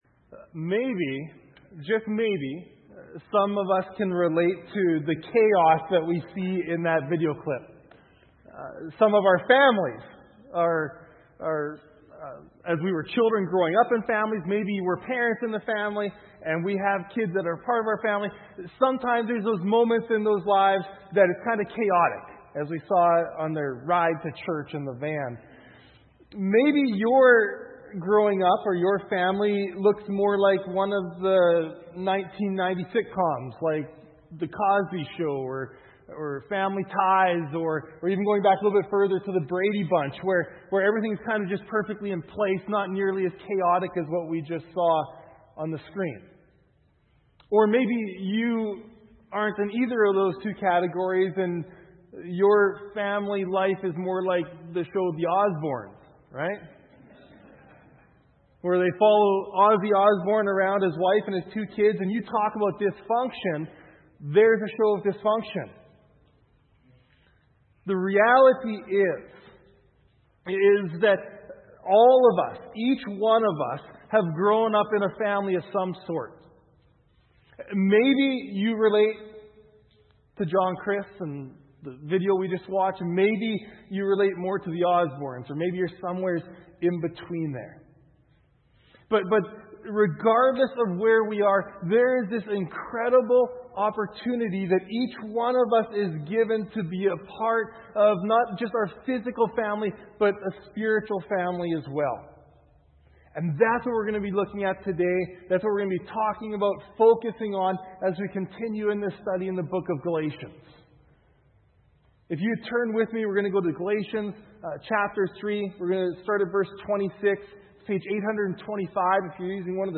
Sermons - Fairland Church